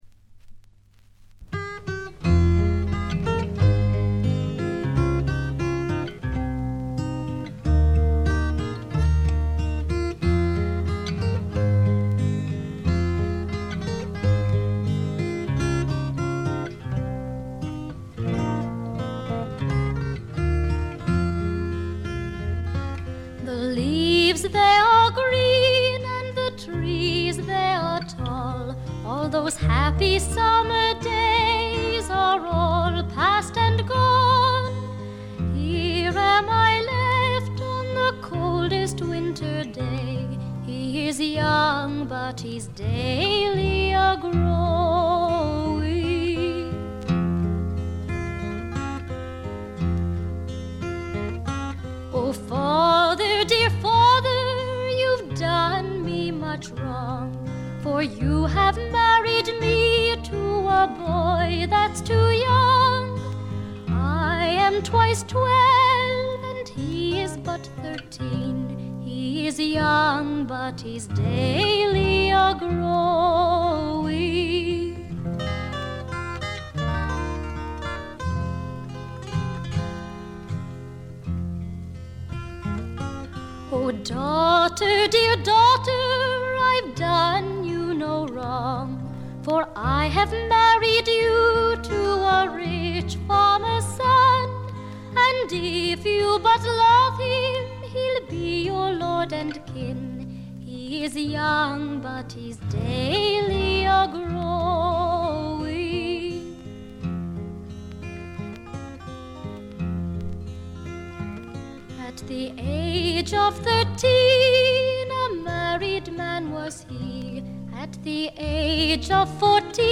わずかなチリプチ程度。
カナダの女優／歌姫による美しいフォーク作品です。
この時点でまだ20歳かそこらで、少女らしさを残したかわいらしい歌唱がとてもよいです。
試聴曲は現品からの取り込み音源です。